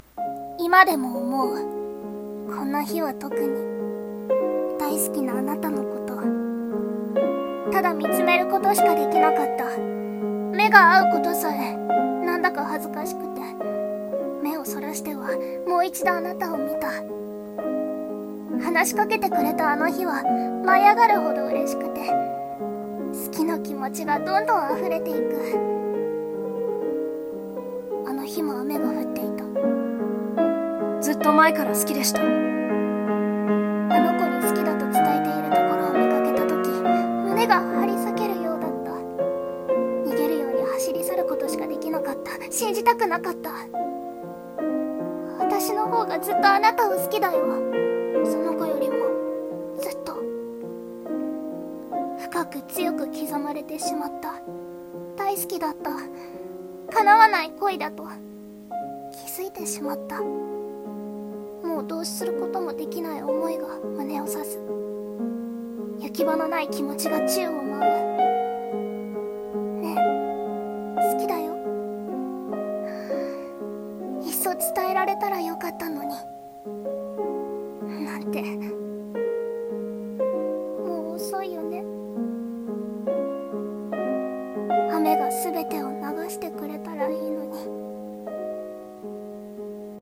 【声劇台本】雨の日の片想い。